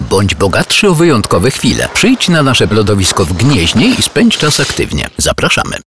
Celebrity Male 50 lat +
Voice artist with 45 years of professional experience.